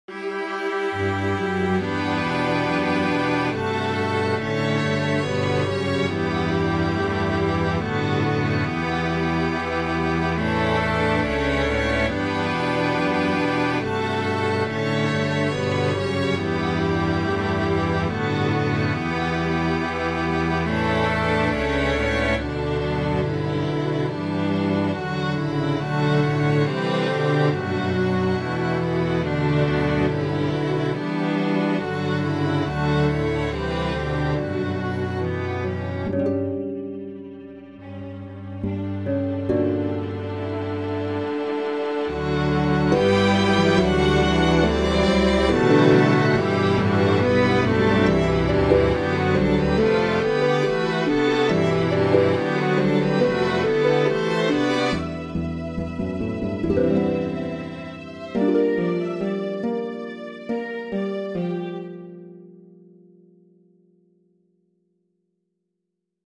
a piece for string orchestra